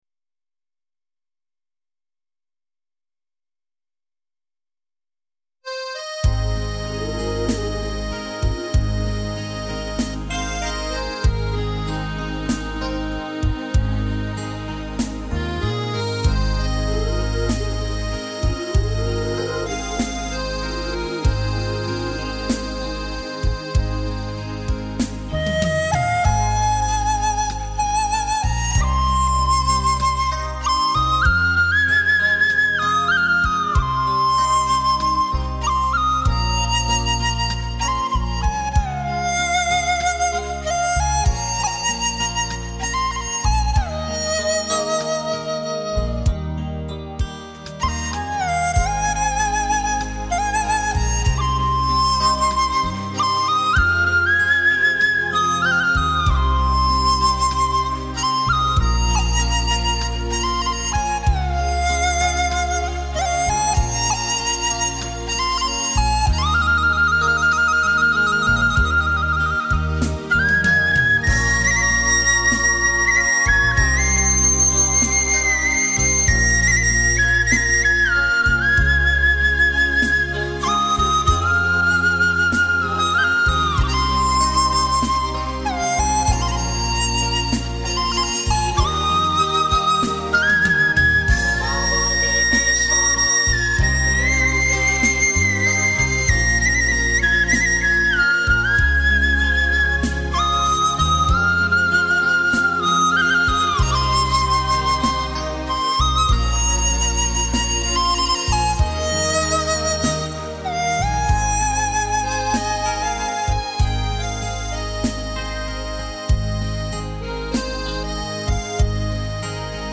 清新的田园，悠扬的笛声！
笛声时而高亢明亮，如江中流水。
竹笛音质悠扬清脆，适于表现婉转的情致和热烈欢快的情感，聆听吧！